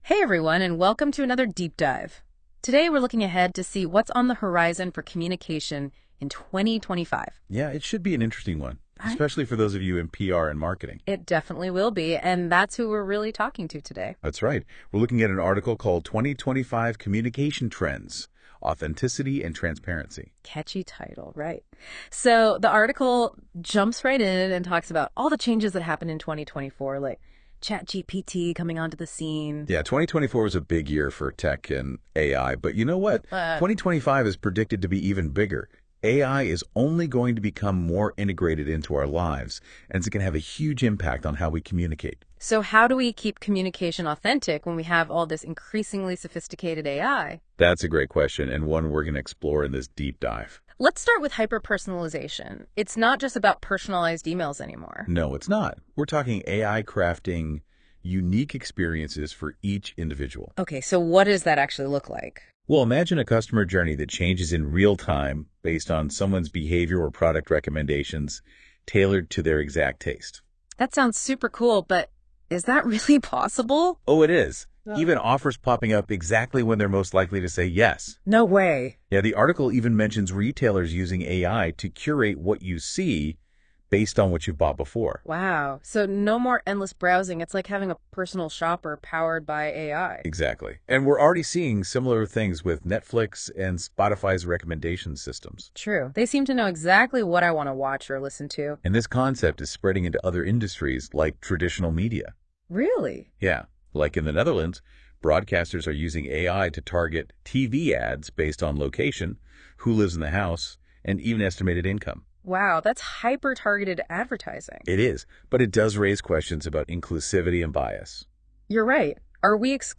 Hieronder word je er binnen een paar minuten doorheen gepraat, powered by Google’s NotebookLM.